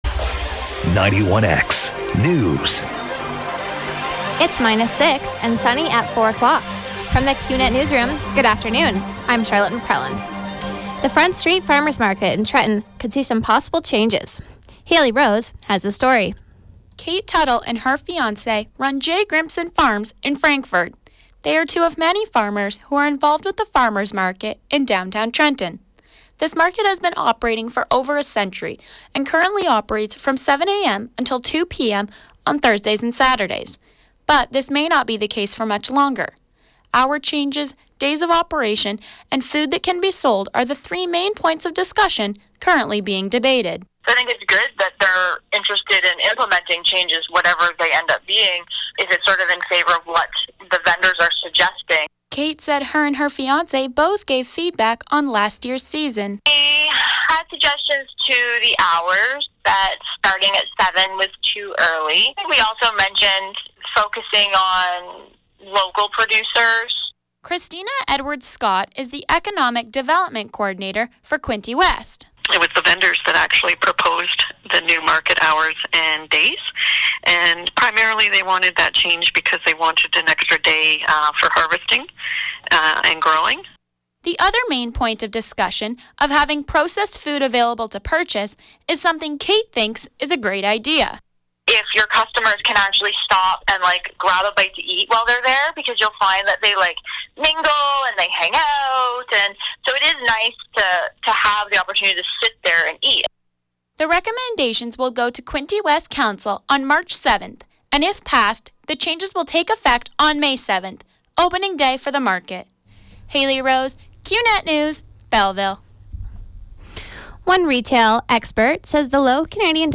91X-4pm-newscast.mp3